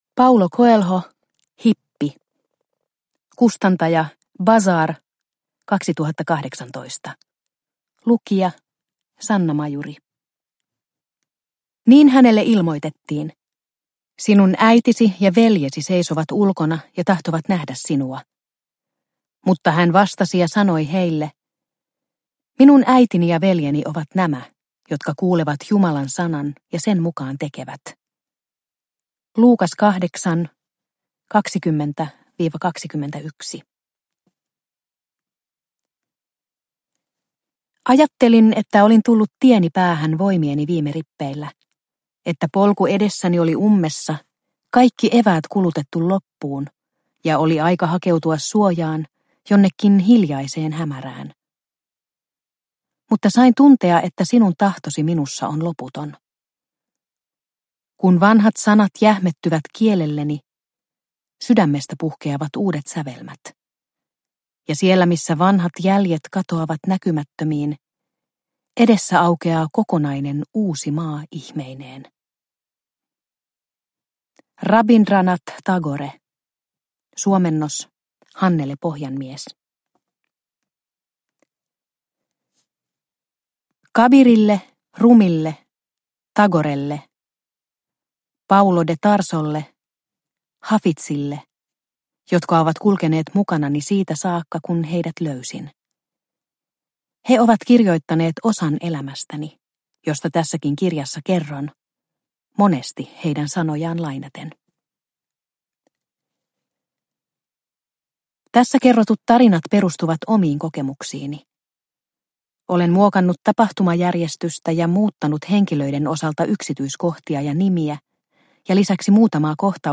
Hippi – Ljudbok – Laddas ner